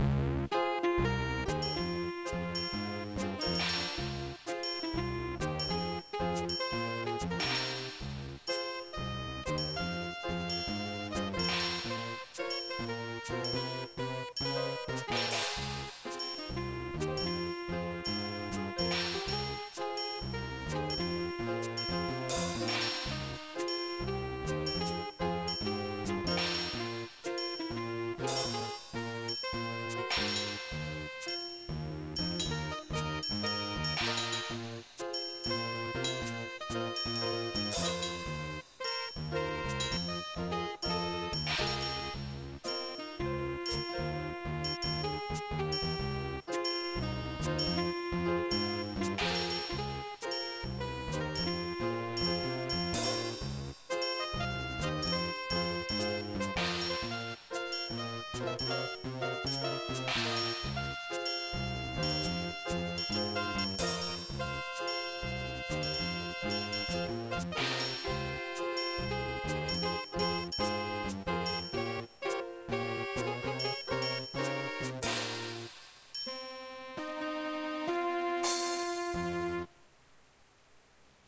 Another jazz tune I really don't know how to make my midi tunes into high quality ogg vorbis.pieces, so you might have to request the midi file so you can mix the qualityyourself or change the instruments.